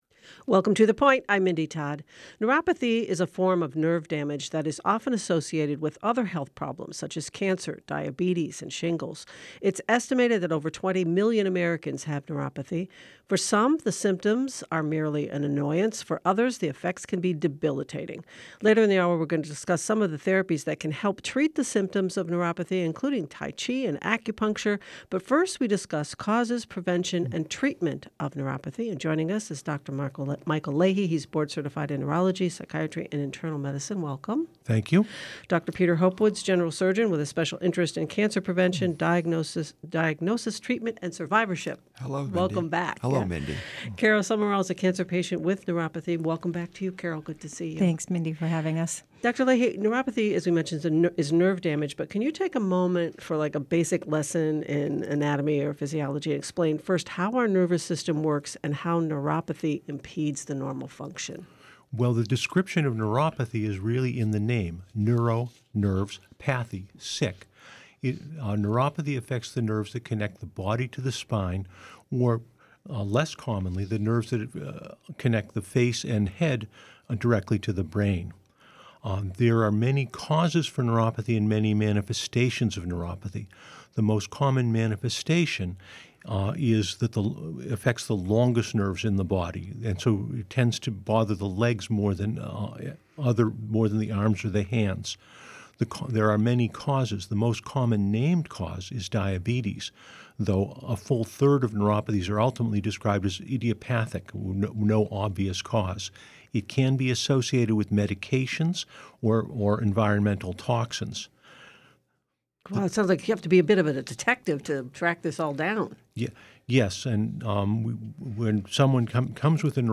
WCAI's award-winning public affairs program.